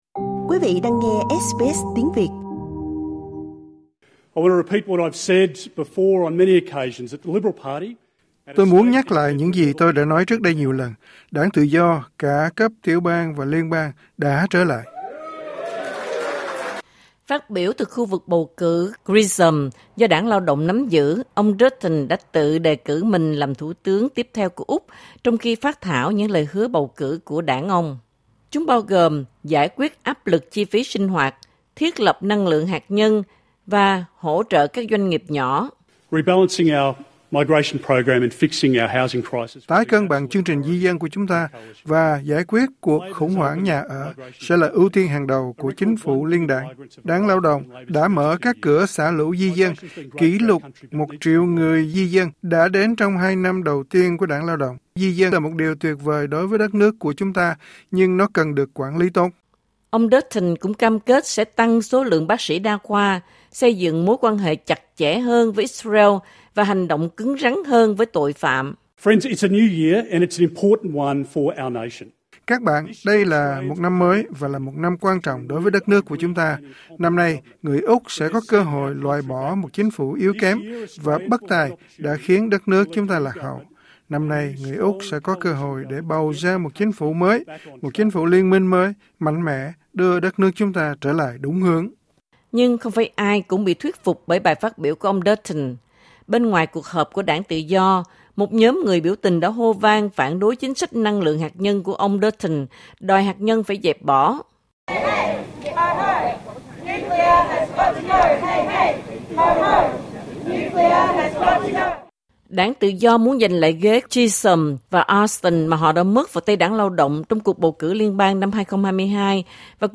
Leader of the Opposition Peter Dutton has unofficially launched his election campaign at a rally in Melbourne.